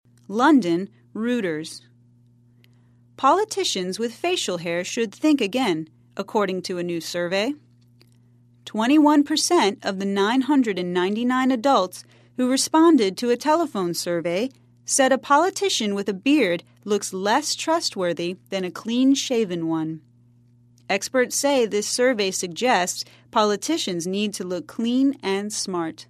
在线英语听力室赖世雄英语新闻听力通 第65期:蓄胡子的政治人物的听力文件下载,本栏目网络全球各类趣味新闻，并为大家提供原声朗读与对应双语字幕，篇幅虽然精短，词汇量却足够丰富，是各层次英语学习者学习实用听力、口语的精品资源。